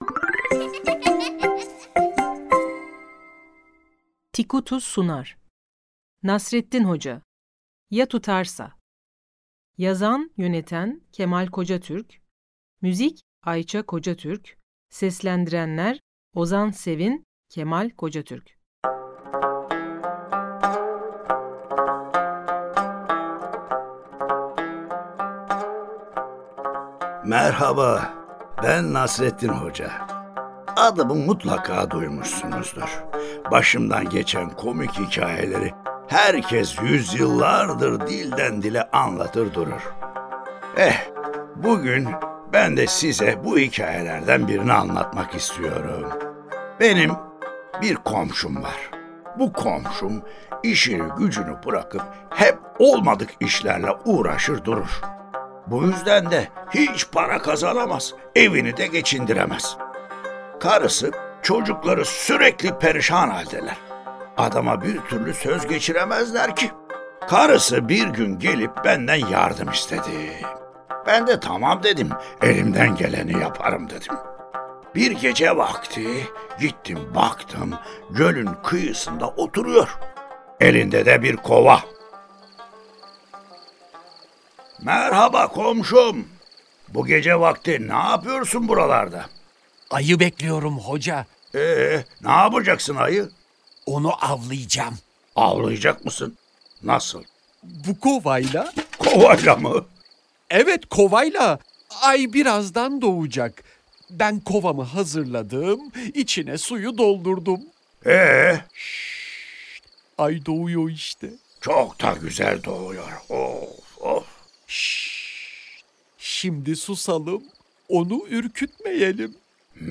Nasreddin Hoca: Ya Tutarsa Tiyatrosu